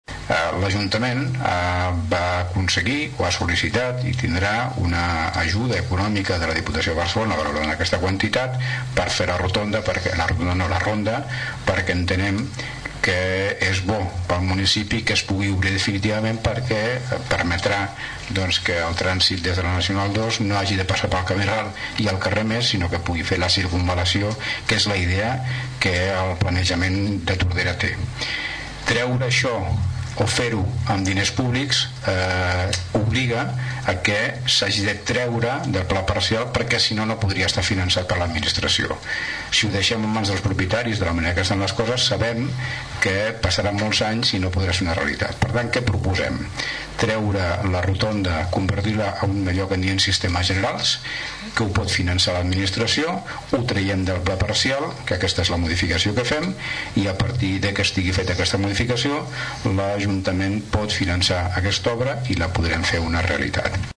Explicava la modificació l’alcalde de Tordera, Joan Carles Garcia.